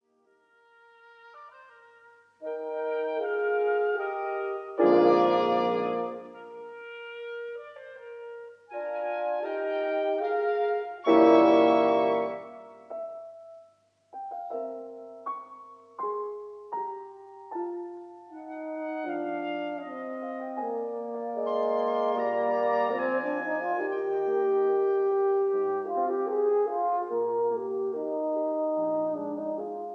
piano
oboe
clarinet
horn
bassoon